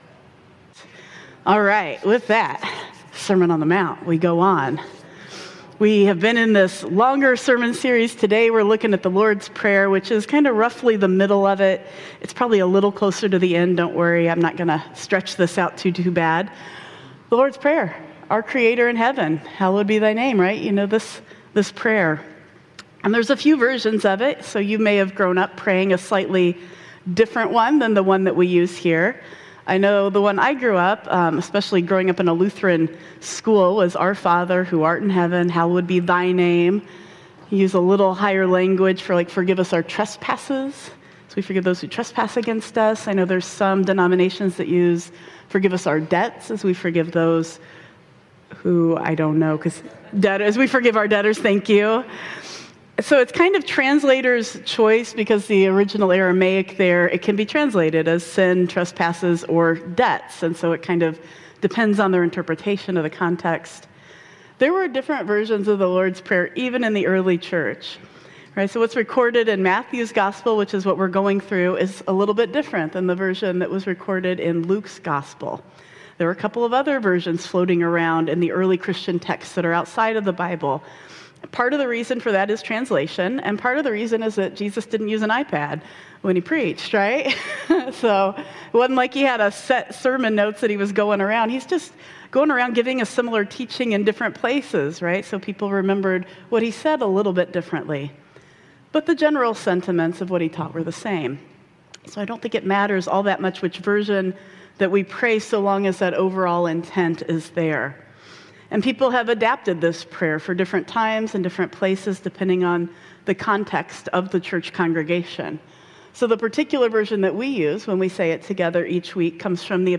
26 Oct 2025 | Sermon on the Mount: The Lord's Prayer - Blue Ocean Church Ann Arbor